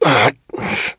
pain_10.ogg